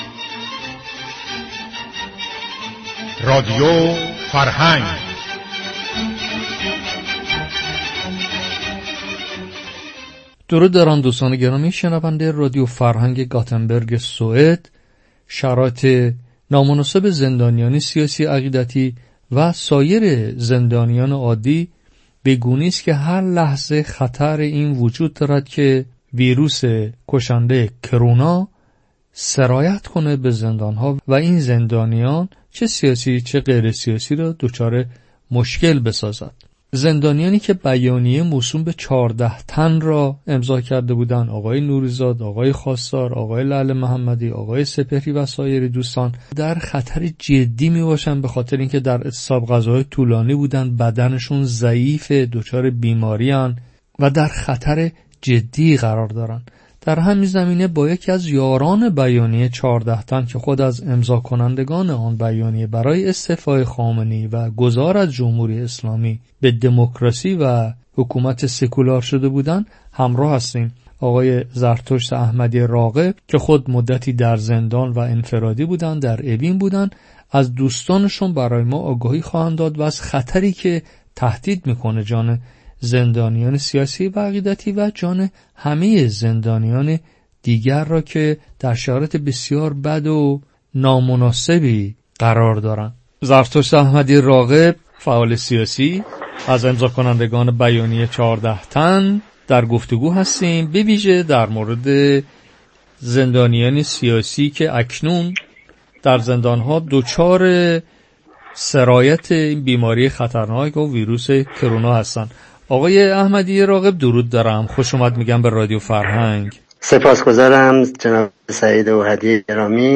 گفت و شنود رادیو فرهنگ